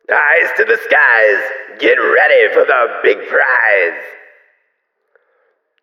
🌲 / midnight_guns mguns mgpak0.pk3dir sound announcer